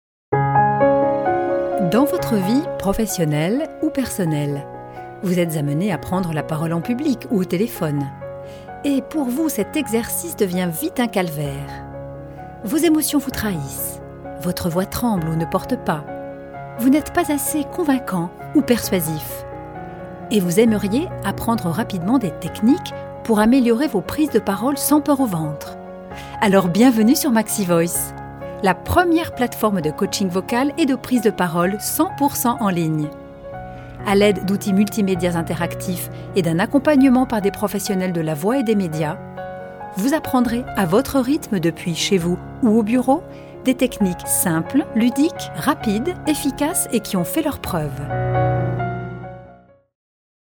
Comédienne et Comédienne Voix
Sprechprobe: Industrie (Muttersprache):